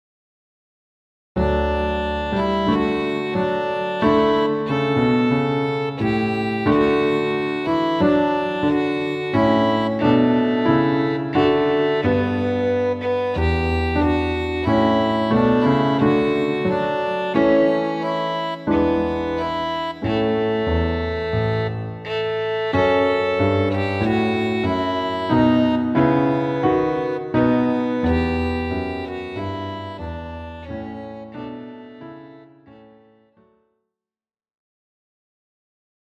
MP3 Sample